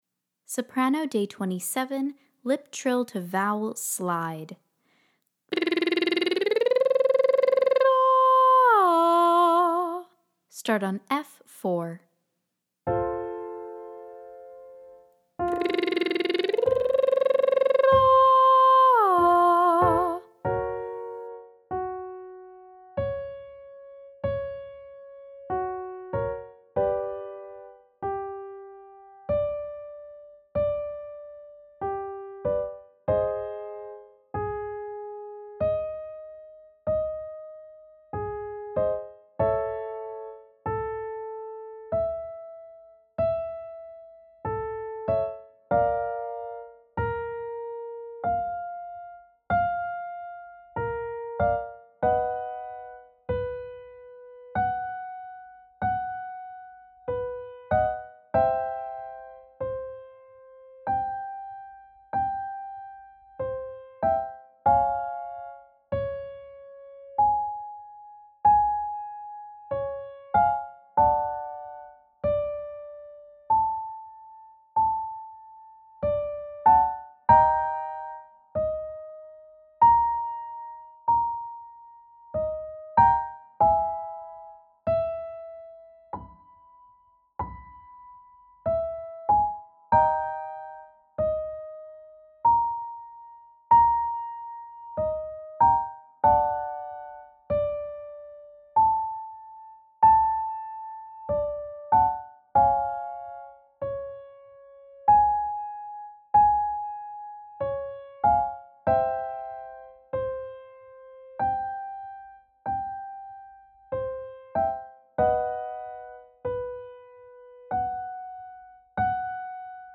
• Exercise: Lip trill to vowel slide (pattern: 1-5-1)
• Connect to breath on lip trill, opening up to vowel of choice 1-5-1
Day 27 - Soprano - Lip Trill To Vowel